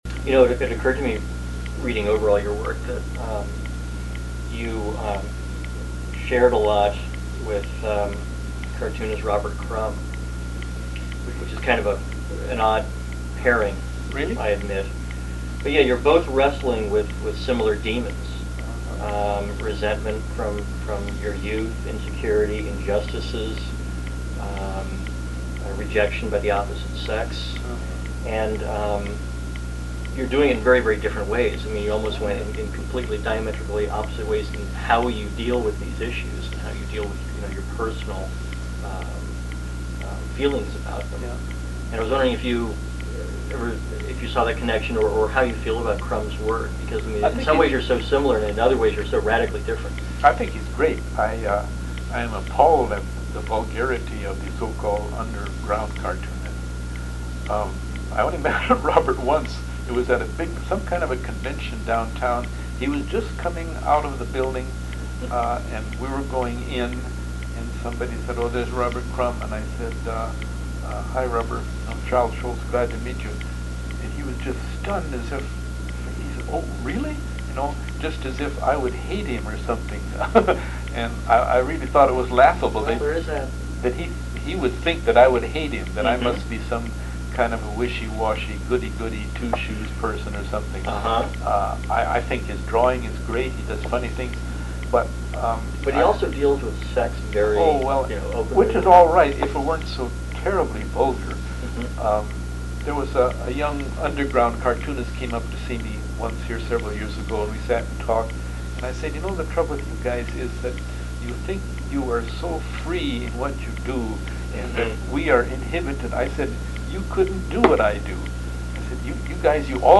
The Comics Journal audio interview with Charles Schulz from 1997 Note:� This is a large (10MB) file